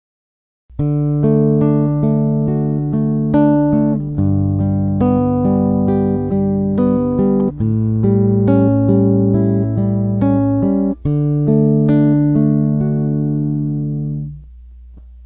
アルペジオ演奏例・Ｄ
Ｄ （ディー）Ｇ （ジー）Ａ７ （エーセブンス）
arpeg_d.mp3